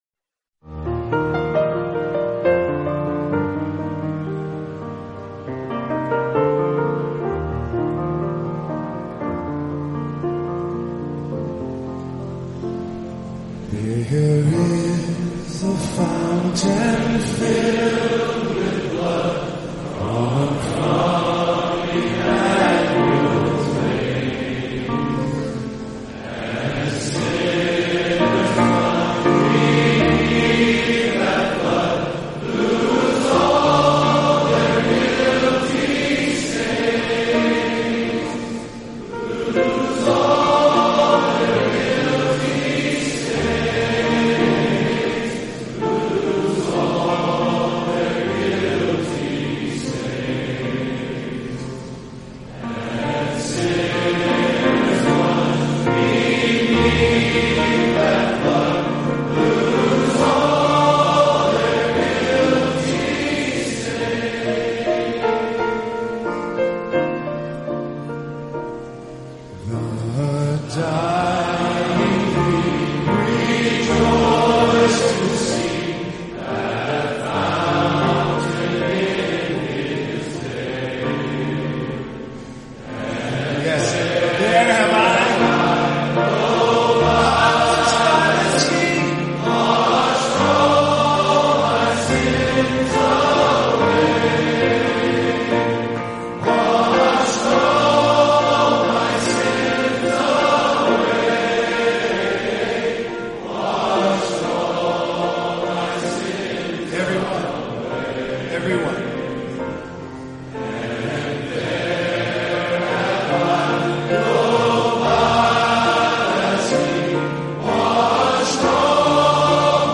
Congregational singing